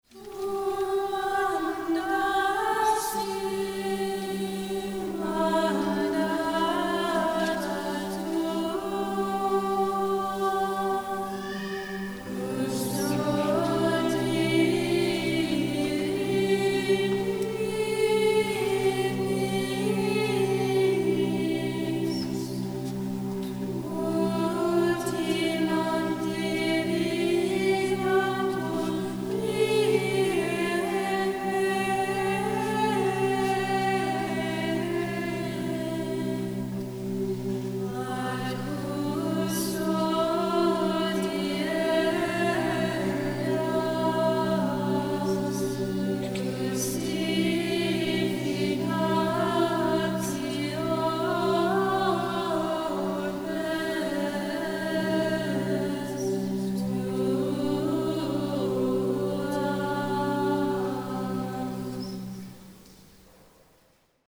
Once again, the treble voices began the antiphon and helped lead the tenors into their drone on “Do.” At the start of the third bar, the basses were then guided to their pitch on “Fa.” The result was a luminous texture of sound—two strong pedal tones supporting the chant melody like architectural pillars.
Mp3 Download • Live Rec. (Double organum drone)